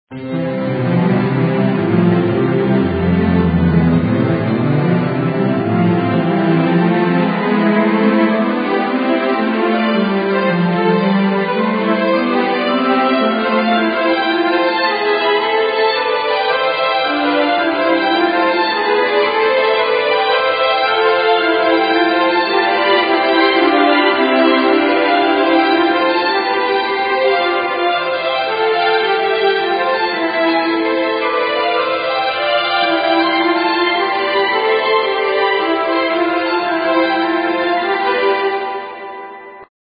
Illusion of Circular Pitch
Click on the Photograph of the ever rising staircase to hear the ever rising scale.
You perceive the tones as continuously increasing or decreasing in pitch; however, after traveling over an octave, they are the same in pitch as when you first started.
The version heard here uses the C major diatonic scale and a four octave range. The scale was generated on a synthesizer and was harmonized by American composer Ruth Schonthal to make it musically more accessible.